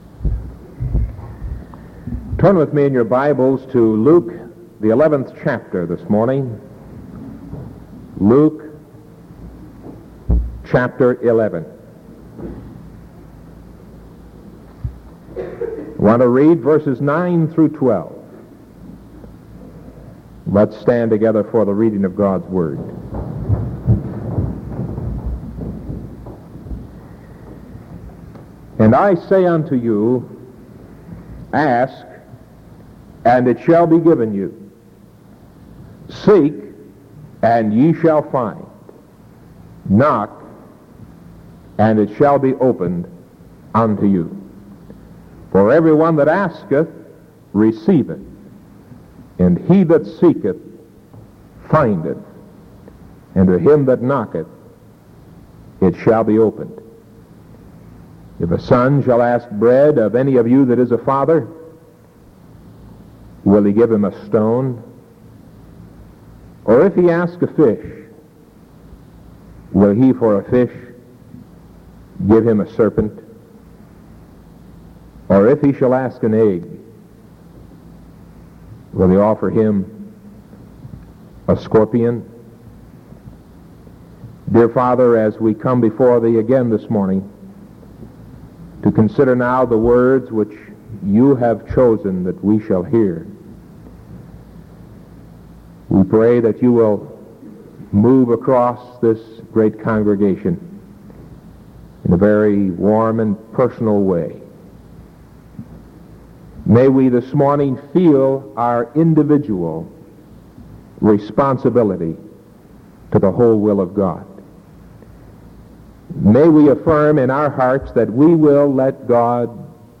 Sermon from Sunday morning, June 17th, 1973.